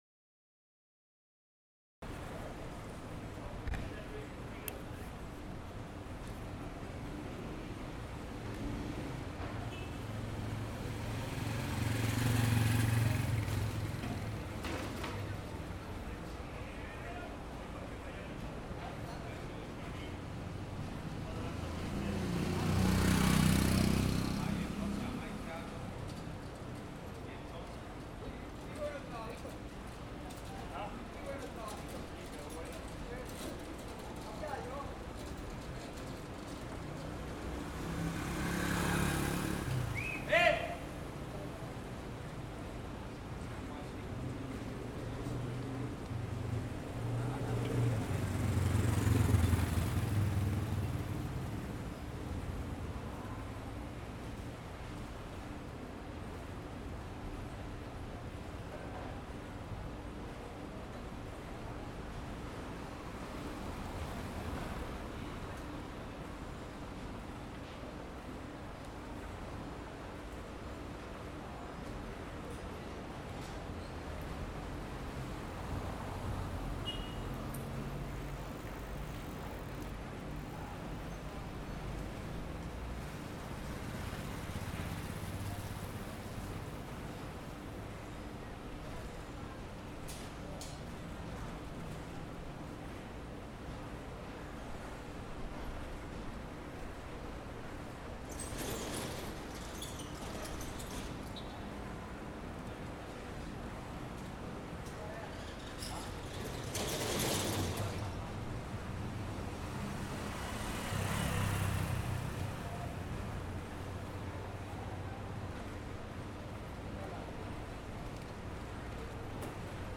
Una troqueladora resuena fuerte, pero deja de trabajar a eso de las 11:45. Hay presencia de carretillas cargadas y vacías que recorren la calle entregando material a diferentes locales. Se escucha la música y el sonido de los radios de algunos locales.